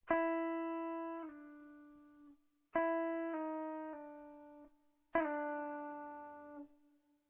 So entsteht ein fließender (von Bund zu Bund), rutschender Sound.
> \ = Slide down to note: Dasselbe in umgekehrter Richtung.
slidedown.ra